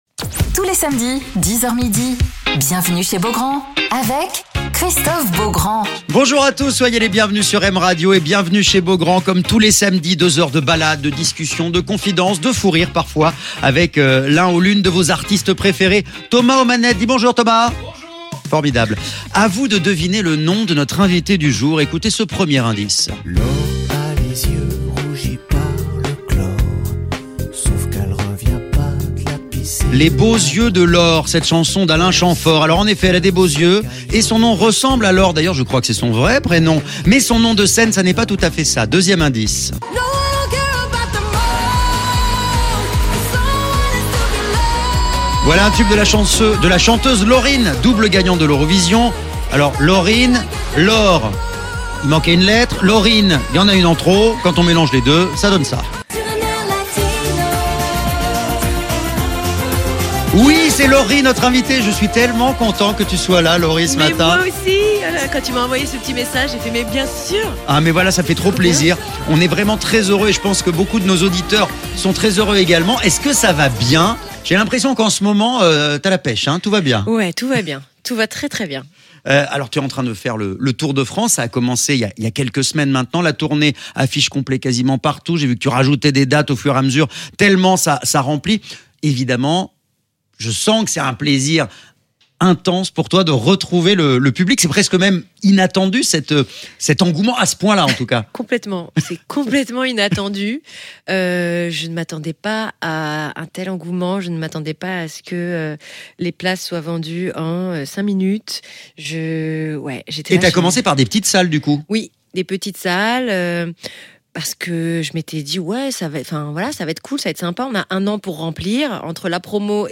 Alors qu'elle est actuellement en tournée, Lorie est l'invitée de Christophe Beaugrand sur M Radio